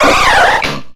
Grito de Primeape.ogg
Grito_de_Primeape.ogg